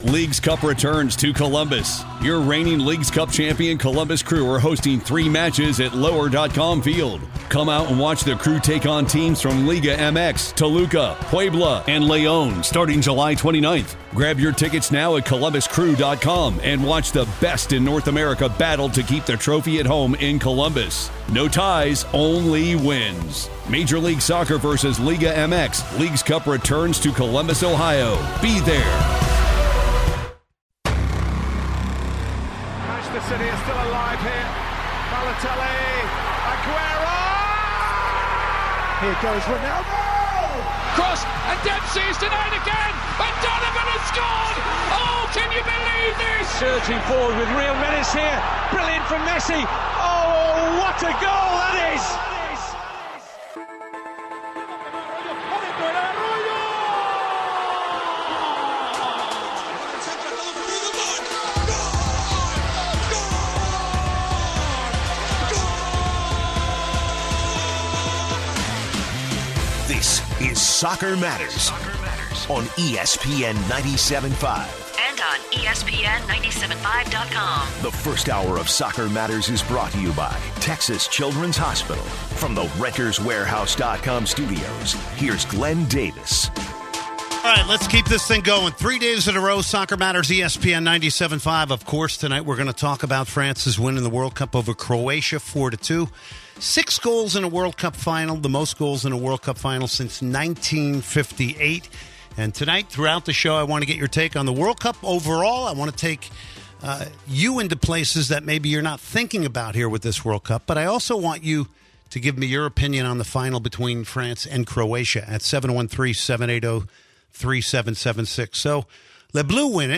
A caller notes that he believes this title will help M’Bappe emerge as one of the top soccer stars on the planet. After the break, a caller notes that if this wasn’t the best World Cup ever, it was the best World Cup group stage ever.